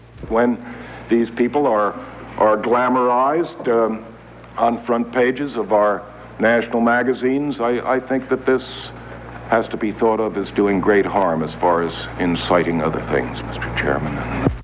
U.S. Secretary of the Treasury William E. Simon testifies before a Senate committee investigating the Secret Service